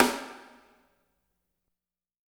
RES SNAREW-L.wav